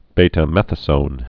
(bātə-mĕthə-sōn, bē-)